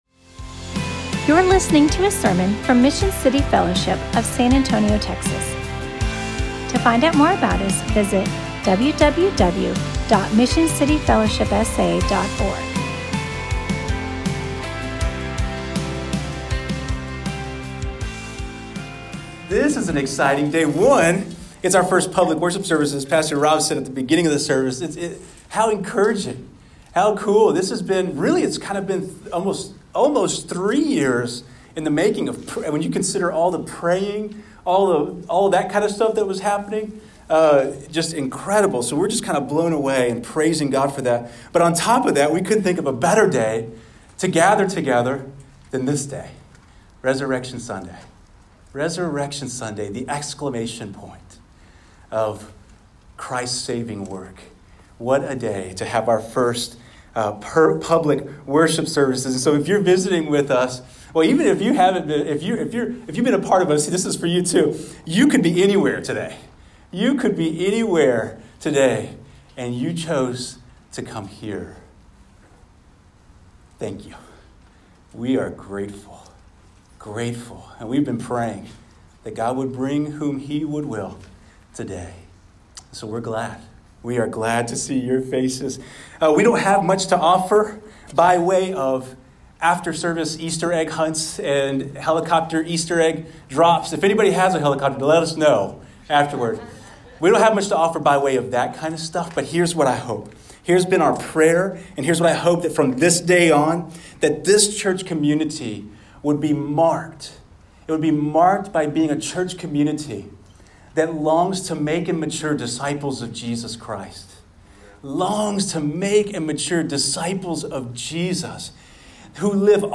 Mission City Fellowship launched their first public worship service on Resurrection Sunday with a message from 1 Peter 1:3-5.
MCF-Sermon-4421.mp3